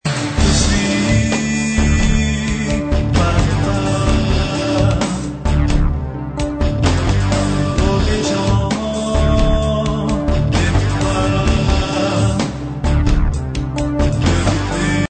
électro-pop